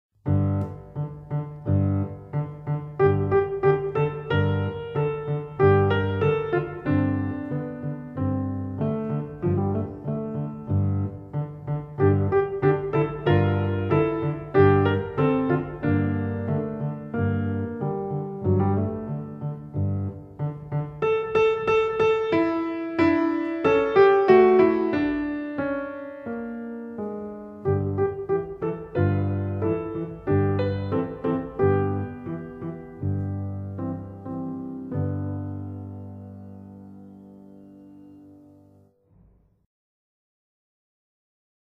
Solo Piano